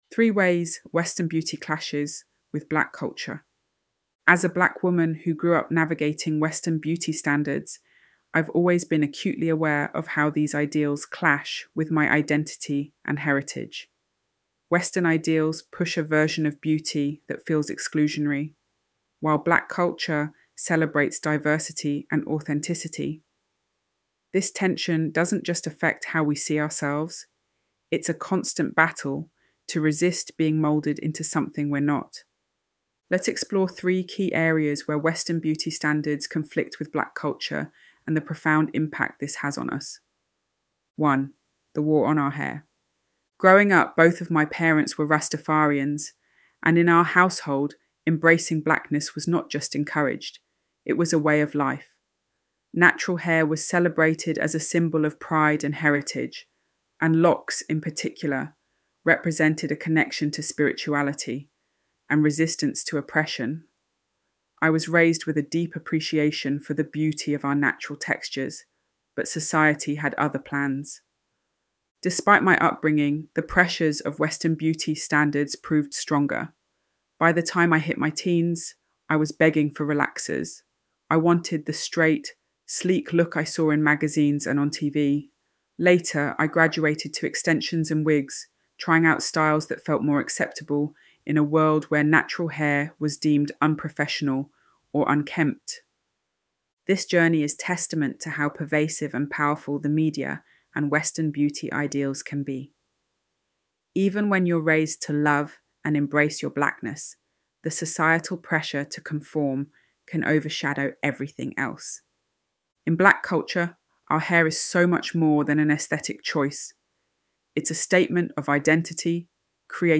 ElevenLabs_3_Ways_Western_Beauty_Clashes_with.mp3